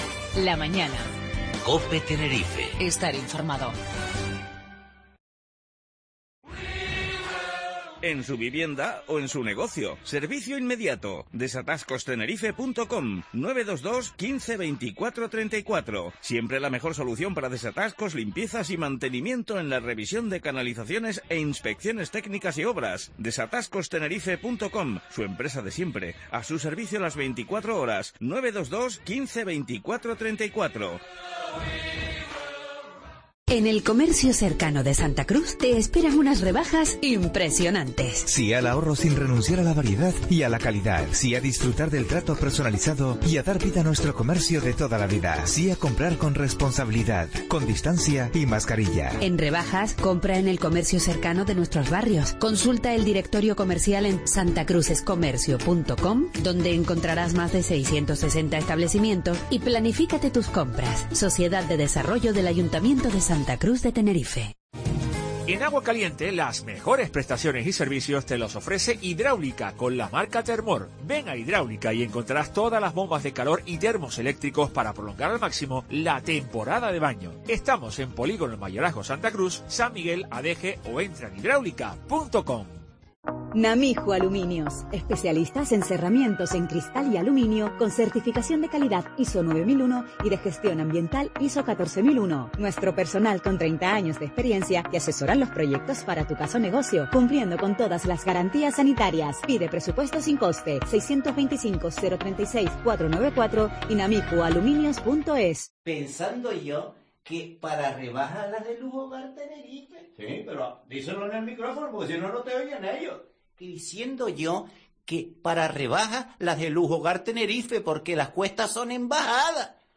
Asier Antona entrevista 11 de enero